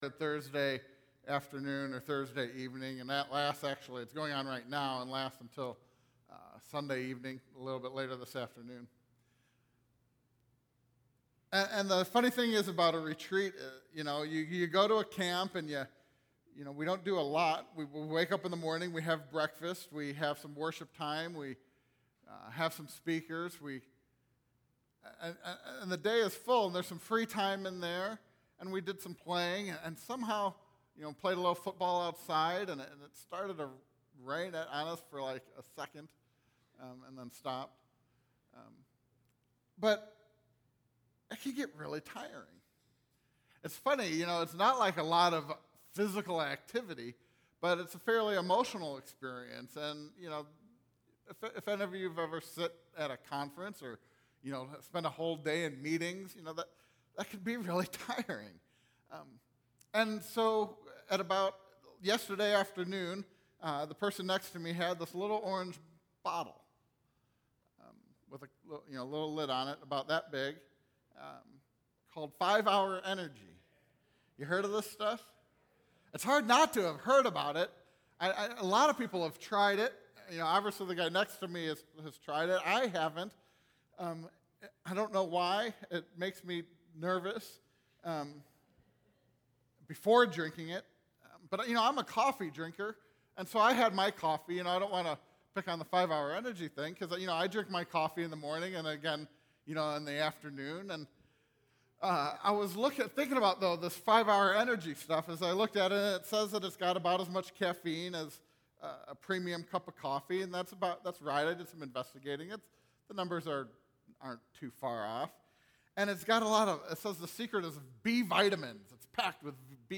Sermon: Whose job is it, anyway?